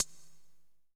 000_hh27closedhh.wav